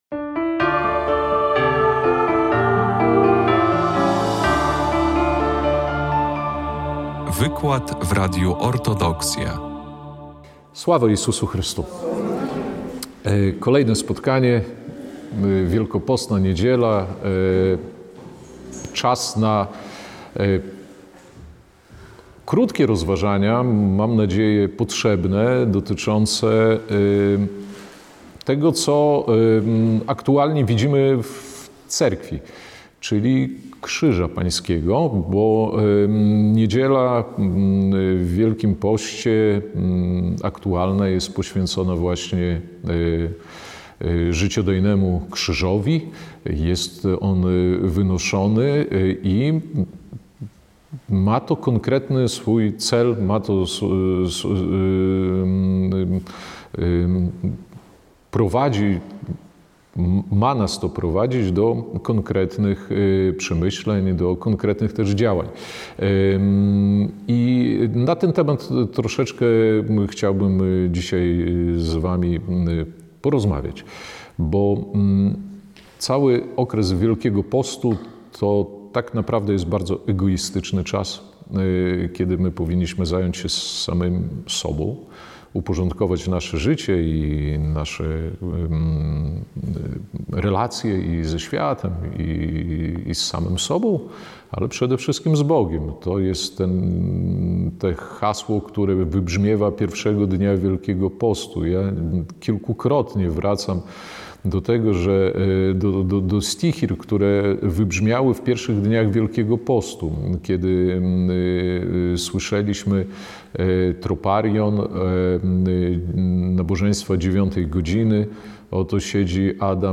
23 marca 2025 r. w parafii Zmartwychwstania Pańskiego w Białymstoku odbył się trzeci wykład w ramach Wielkopostnej Wszechnicy. Spotkanie pt. “Zbawienie poprzez Krzyż”